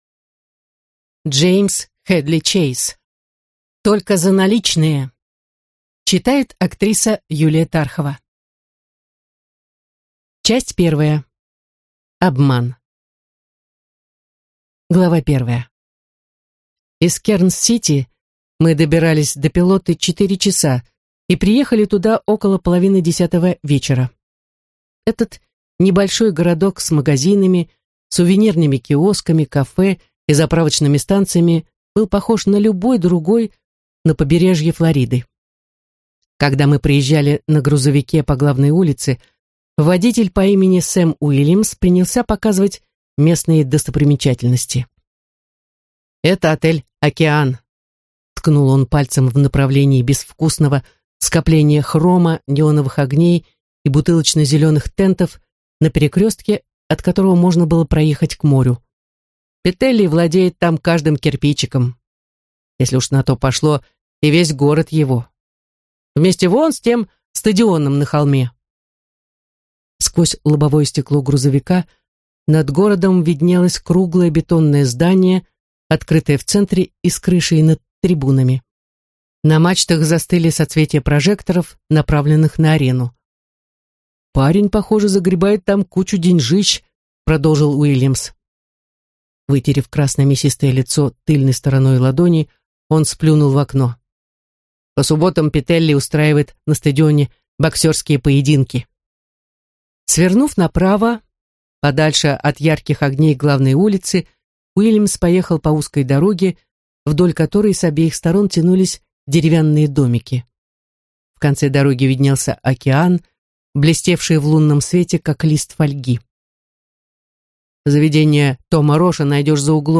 Аудиокнига Только за наличные | Библиотека аудиокниг